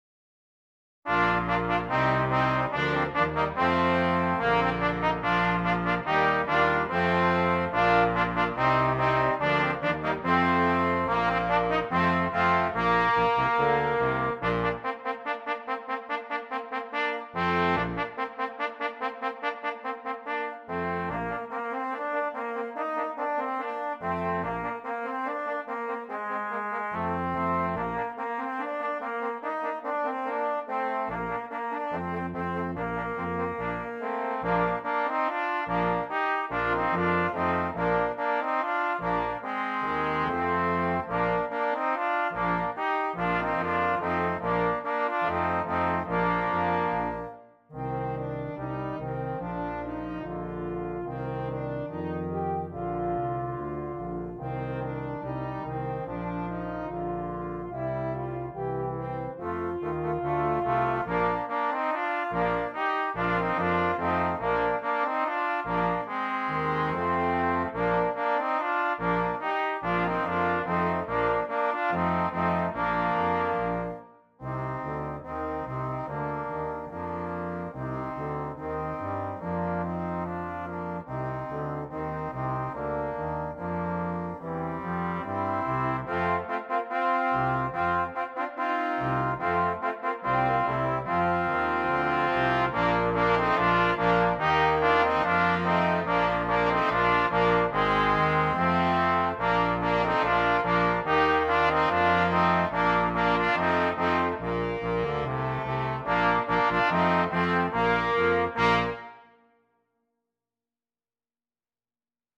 Brass Quintet
Difficulty: Easy Order Code